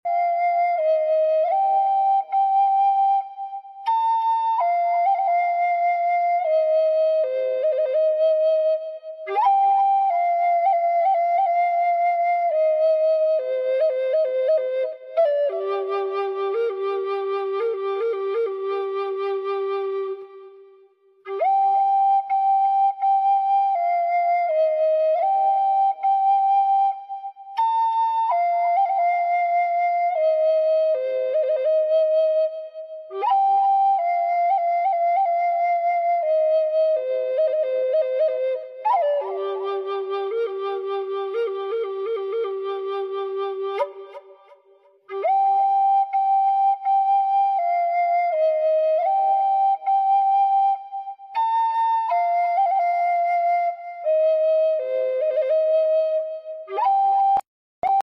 The Real Buffalo Bill and Chief Iron Tail having a chat.